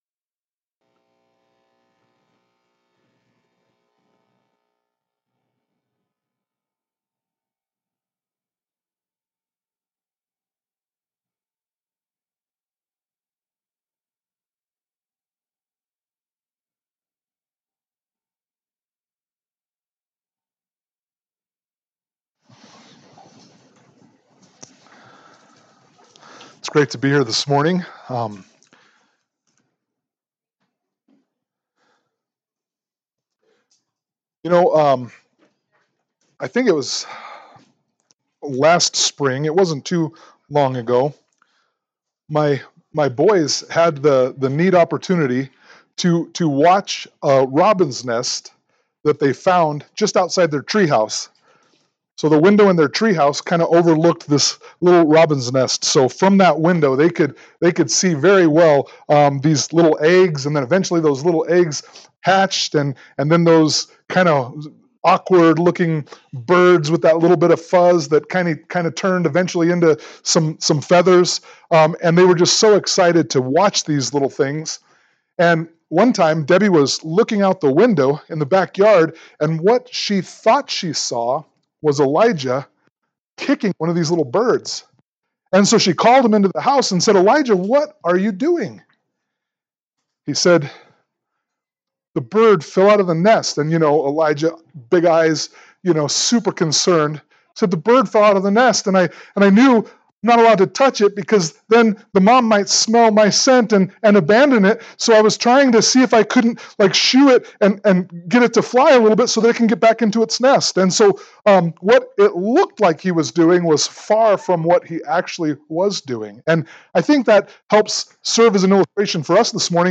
Ecclesiastes 7:15-29 Service Type: Sunday Morning Worship « Ecclesiastes 7:1-14 Ecclesiastes 8